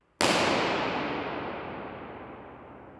Irs_Ambeo-Ambix_primo-piano-1-trim.wav